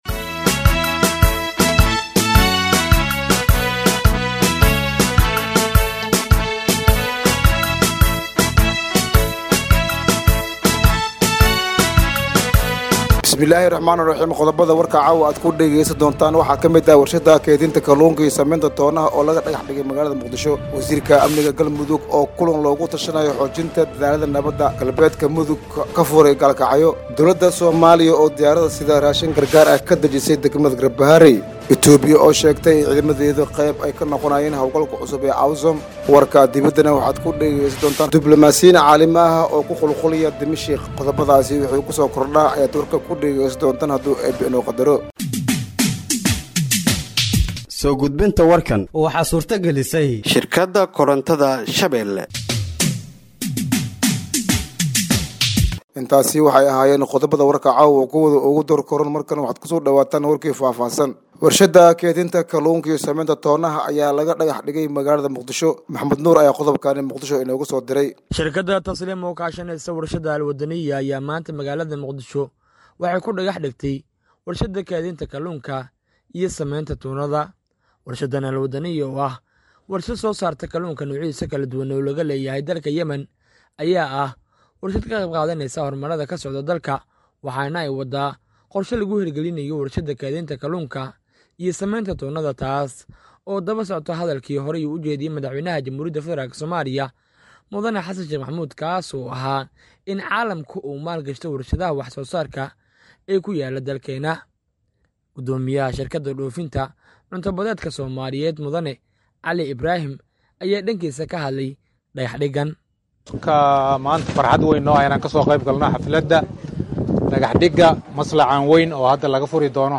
Dhageeyso Warka Habeenimo ee Radiojowhar 03/01/2025